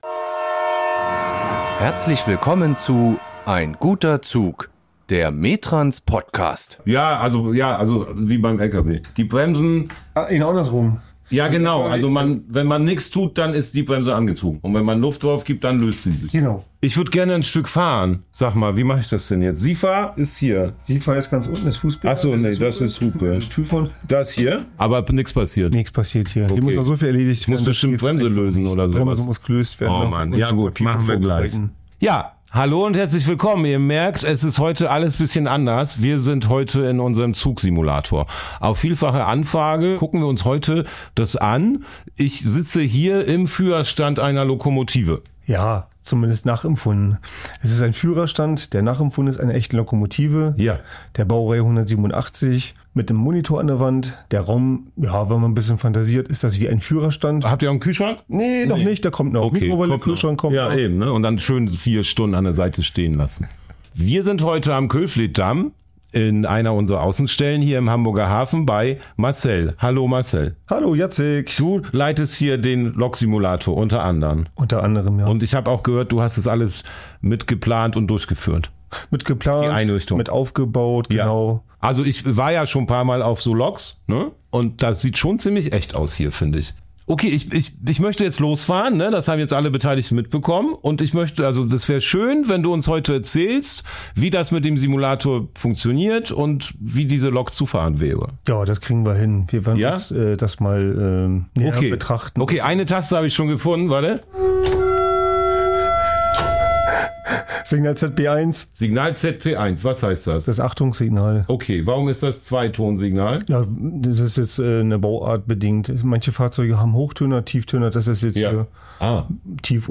Heute ist alles ein bisschen anders: Wir melden uns direkt aus unserem Zugsimulator.